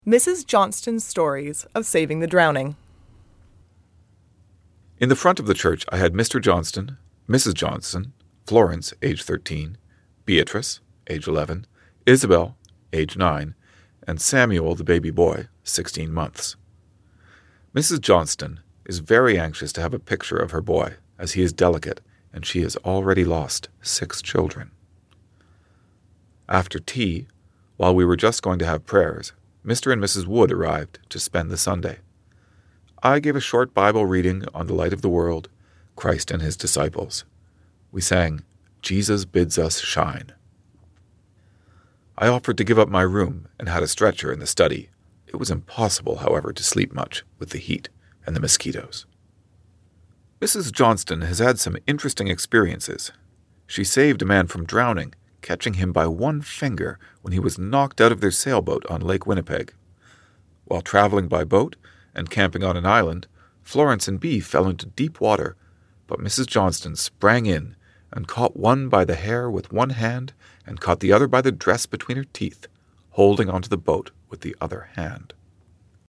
Click on the “play” button to hear the diary episode read aloud, and click on the green tab 1 to learn more about a word or phrase.